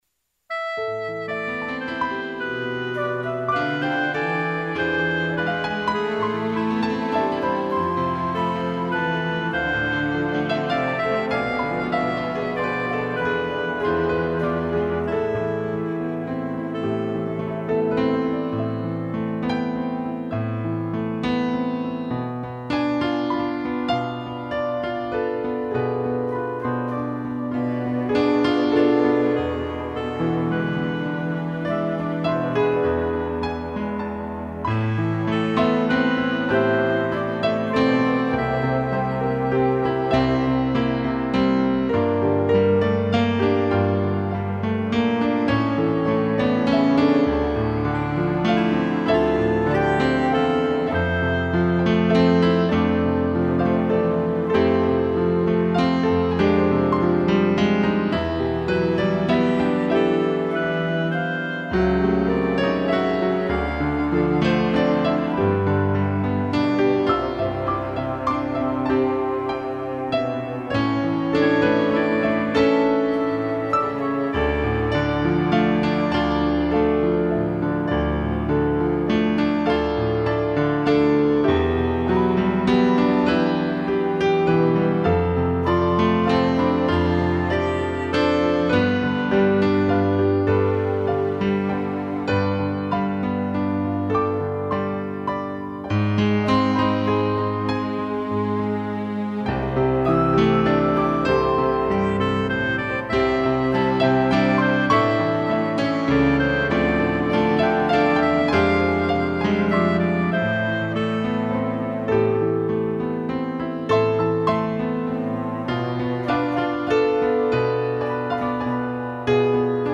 2 pianos, flauta e clarineta
(instrumental)